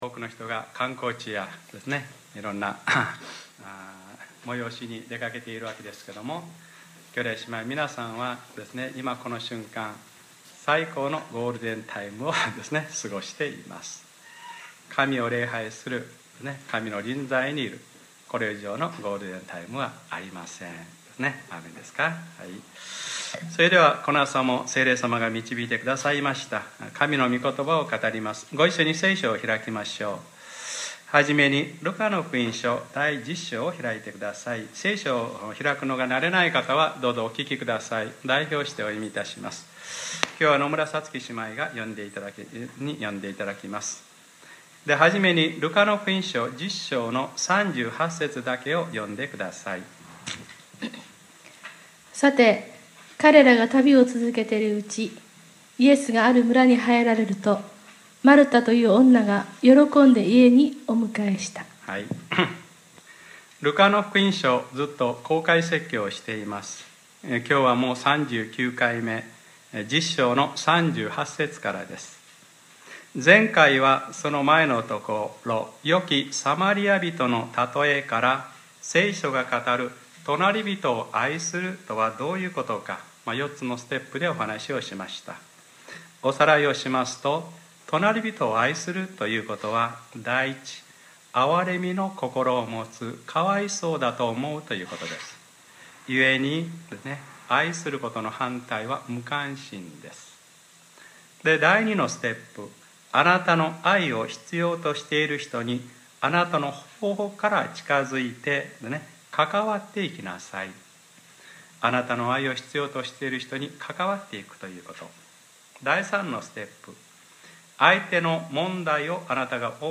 2014年 5月 4日（日）礼拝説教『ルカ-３９：自己発見型聖書の学び』